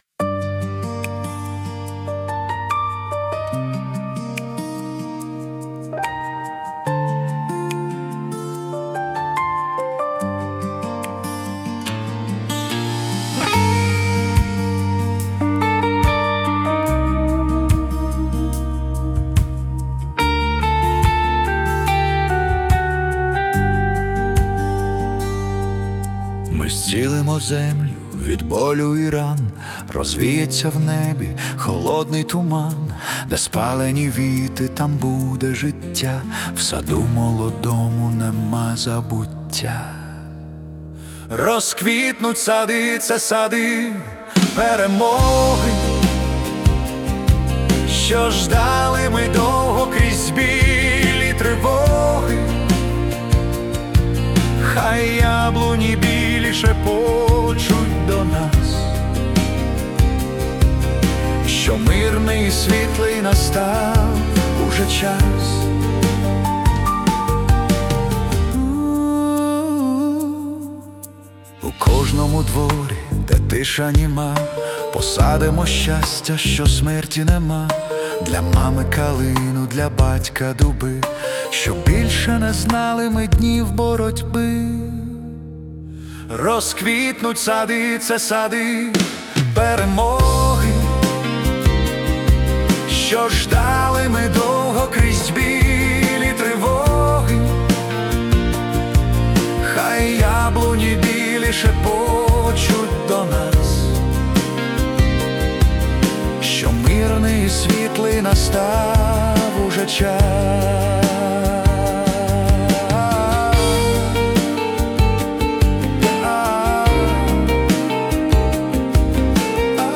🎵 Жанр: Melodic Pop-Rock
це теплий, мелодійний поп-рок (122 BPM)